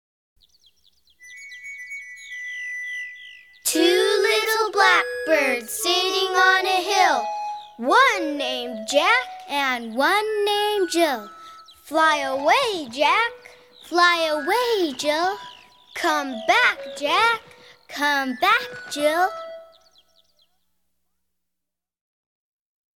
All your favorites are collected in this large selection of 73 traditional songs and fingerplays, sung and chanted by children.